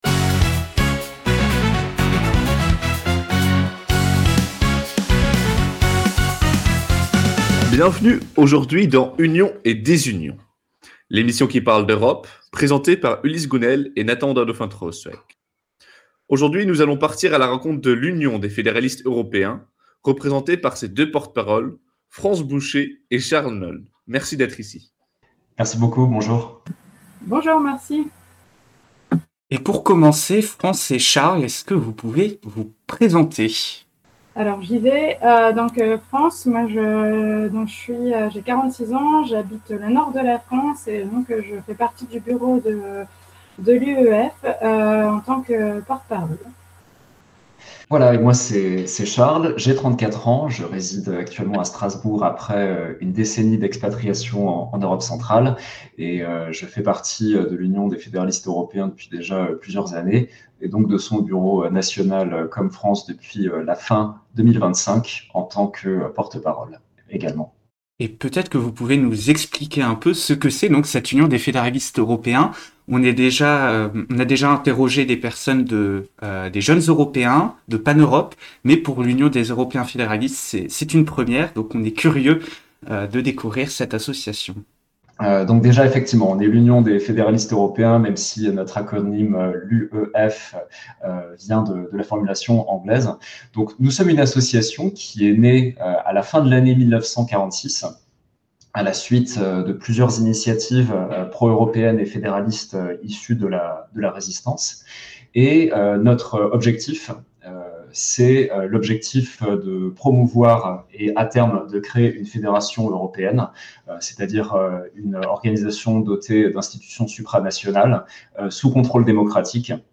deux étudiants européens échangent autour des questions de souveraineté, de compétition commerciale